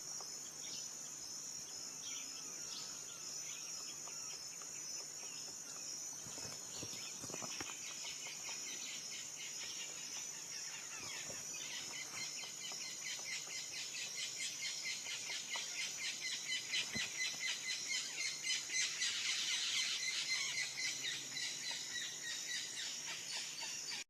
Melanerpes aurifronsGolden-fronted WoodpeckerCarpintero FrentidoradoPic à front doré